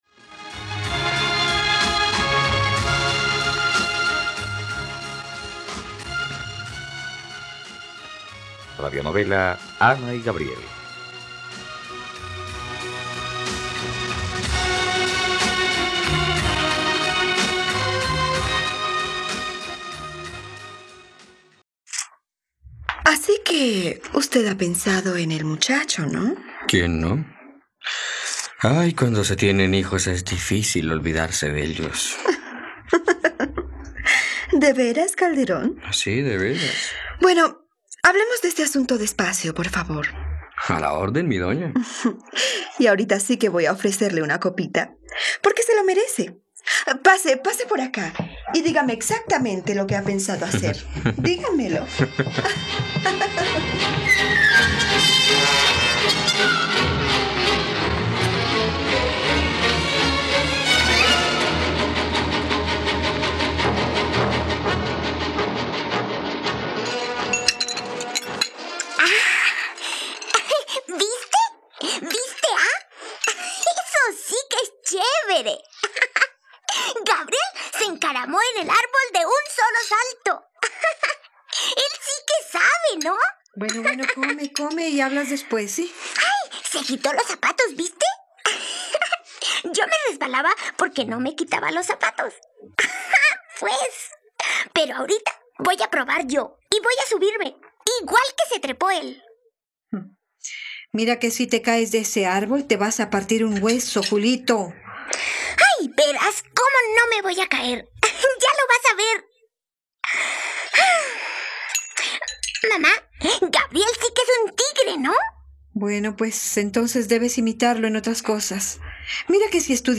Ana y Gabriel - Radionovela, capítulo 83 | RTVCPlay